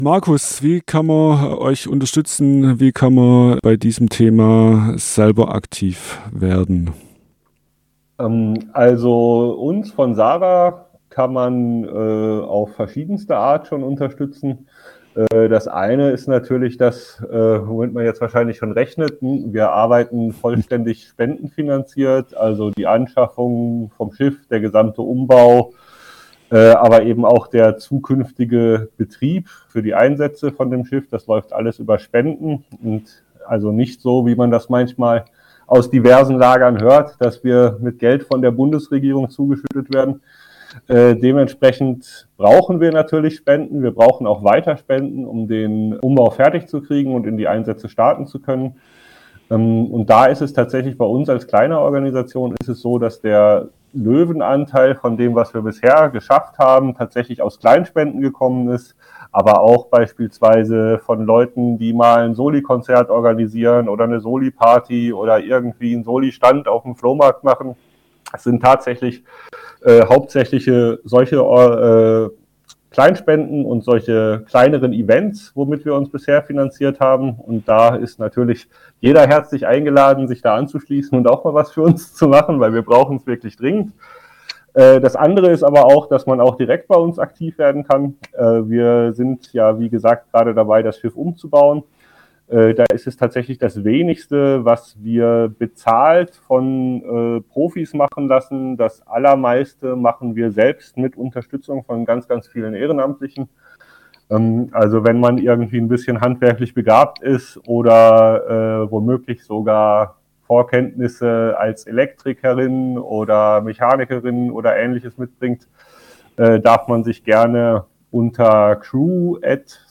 Interview Teil 4